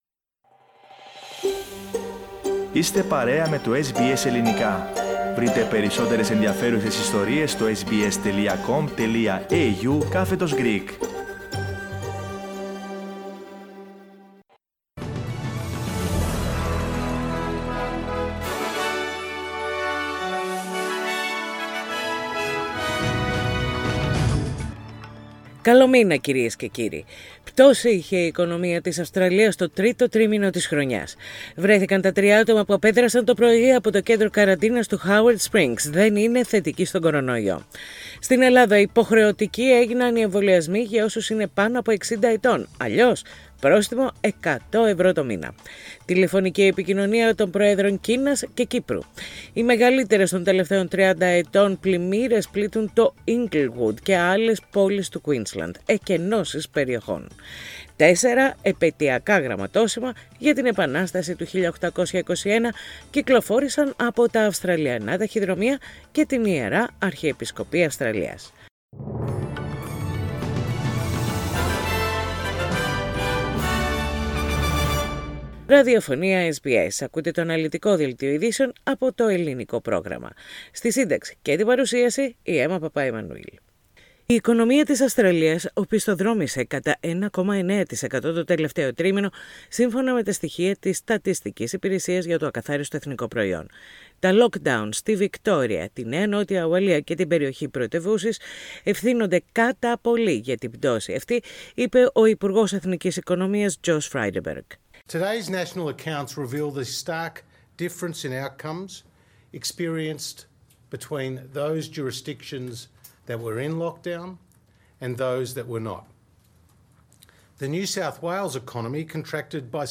Ειδήσεις στα Ελληνικά - Τετάρτη 1.12.21
Ακούστε το αναλυτικό δελτίο ειδήσεων της ημέρας, με τα κυριότερα νέα από Αυστραλία, Ελλάδα, Κύπρο και τον διεθνή χώρο.
News in Greek. Source: SBS Radio